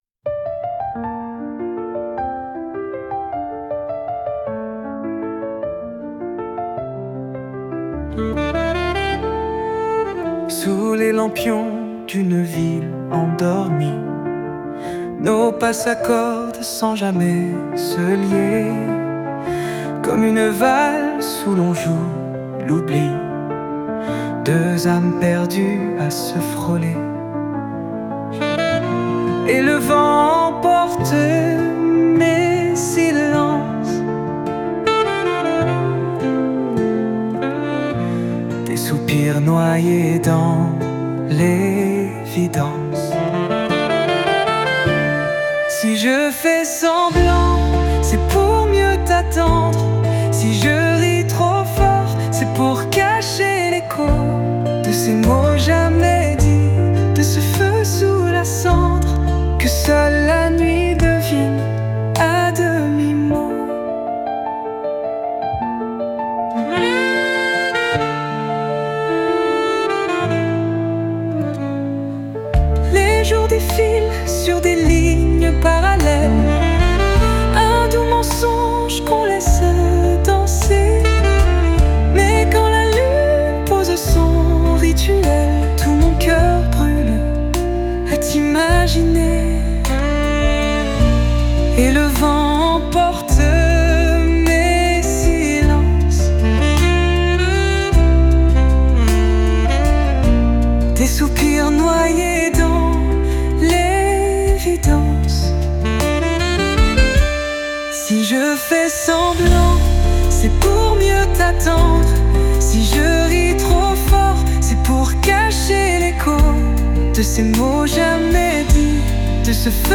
Extrait musical et romantique – Conversation entre Keda et Marc-Antoine
(La musique s’élève, douce et chaloupée, une valse tropicale portée par le souffle du vent sur les montagnes d’Haïti.